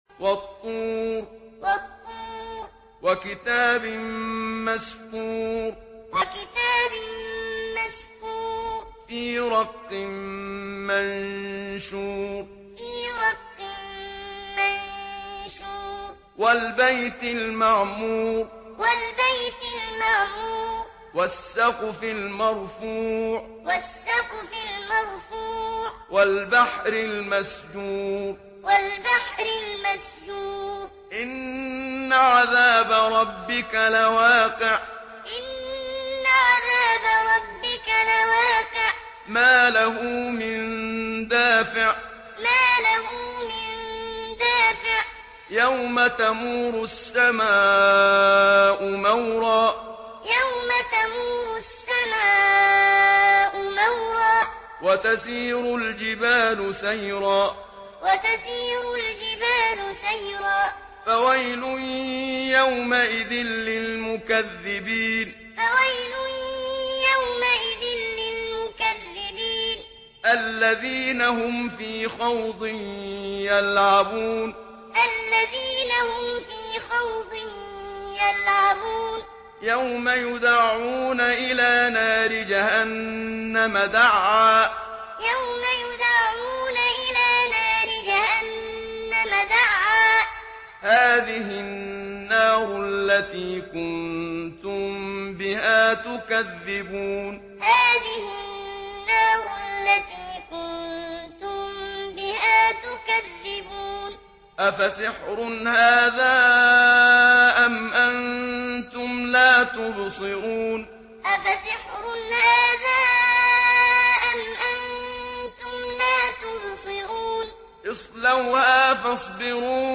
Surah At-Tur MP3 by Muhammad Siddiq Minshawi Muallim in Hafs An Asim narration.
Muallim Hafs An Asim